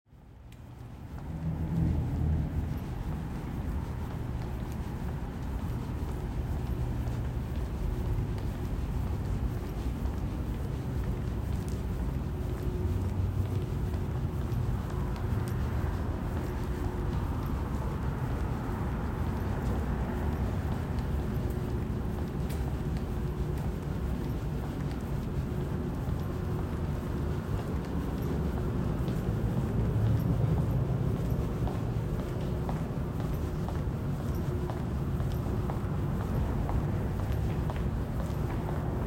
Field-Recording-2-Unispan.m4a